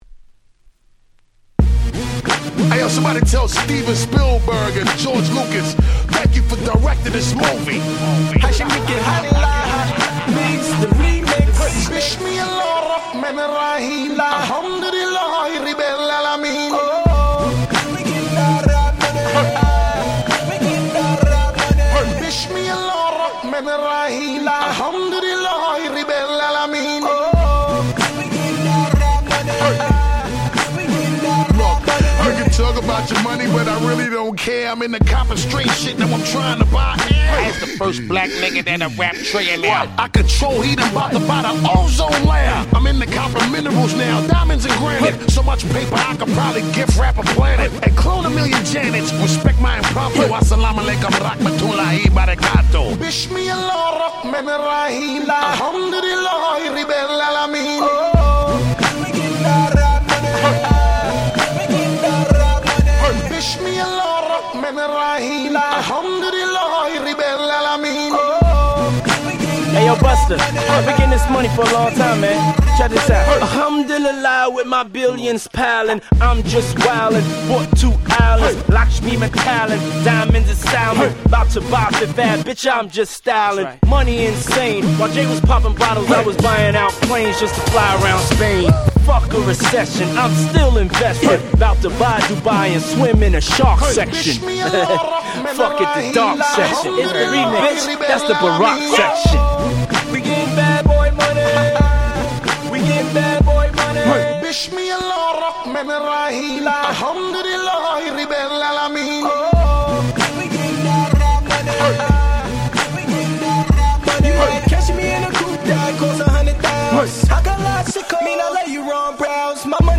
08' Big Hit Hip Hop !!!!!